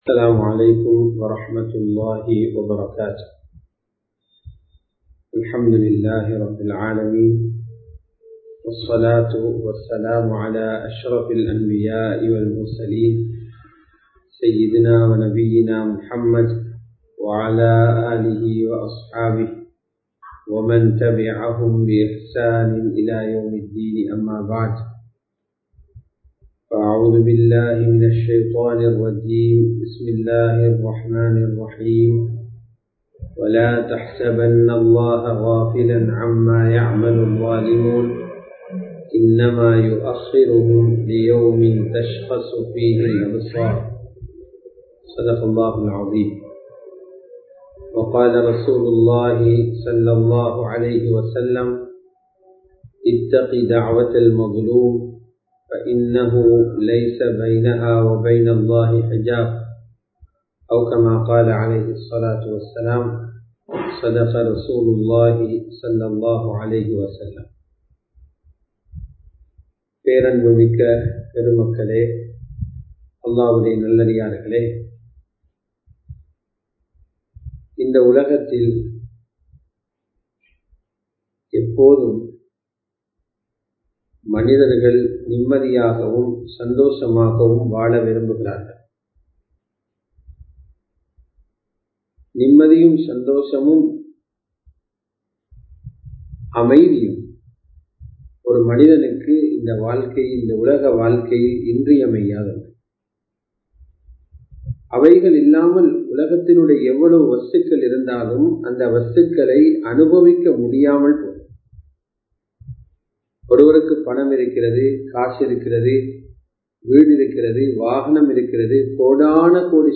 அநியாயக்காரர்களின் முடிவுகள் | Audio Bayans | All Ceylon Muslim Youth Community | Addalaichenai